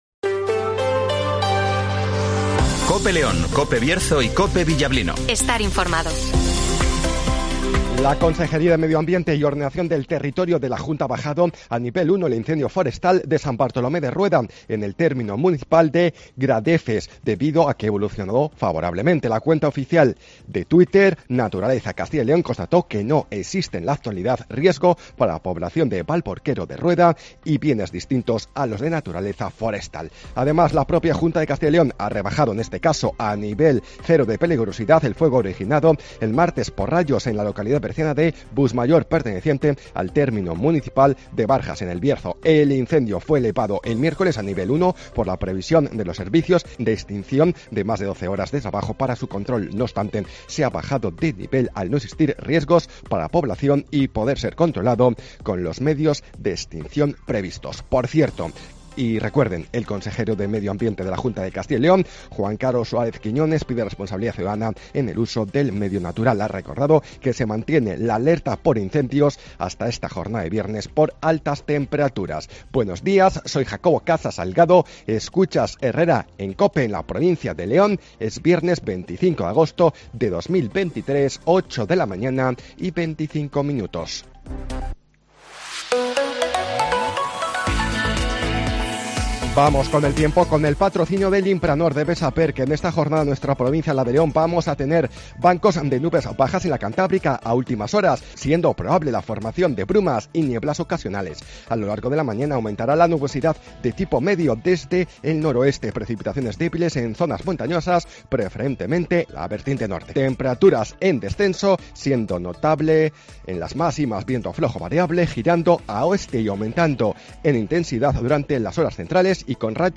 - Informativo Matinal 08:24 h